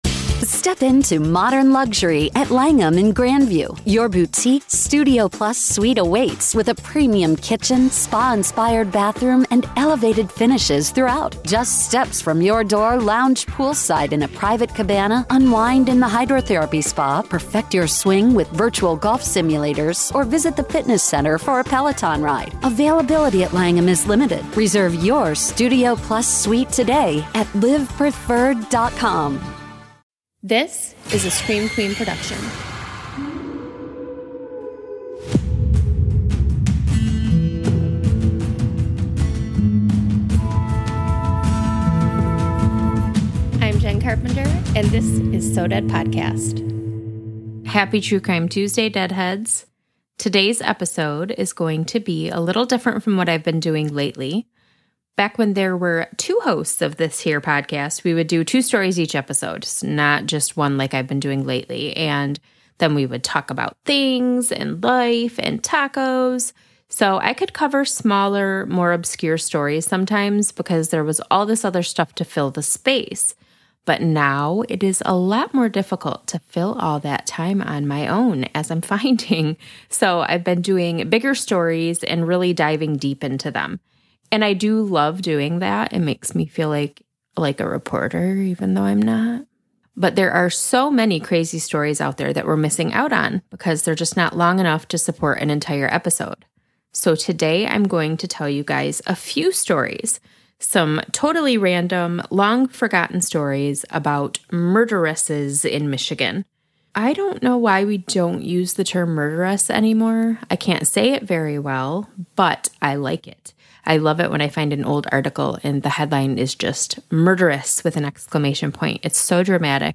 A discussion about infamous Michigan murderesses.